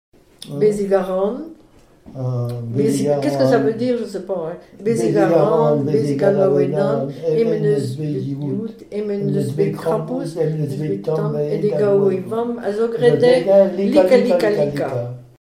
formulette enfantine : jeu des doigts
Témoignages et chansons
Pièce musicale inédite